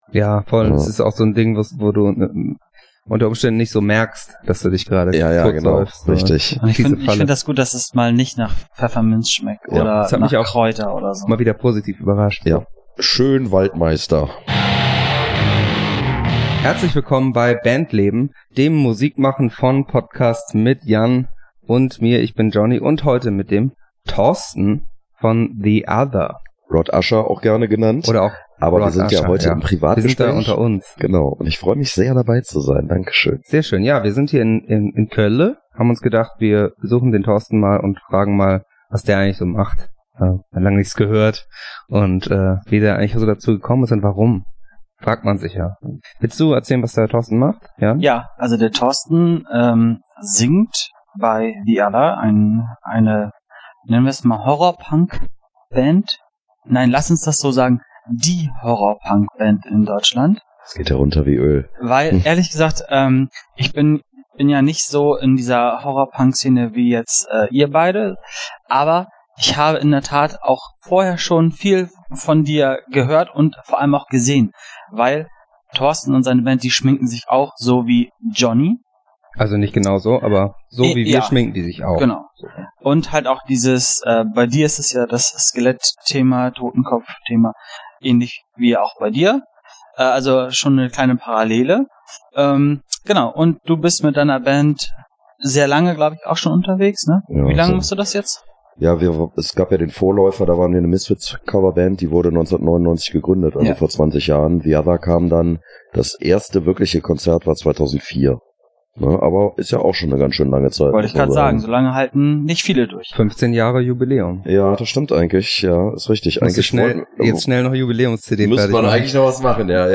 *Die etwas schlechtere Audioqualität im Verhältnis zu sonst bitten wir zu entschuldigen, unser mobiles Setup muss noch etwas optimiert werden, wir arbeiten dran.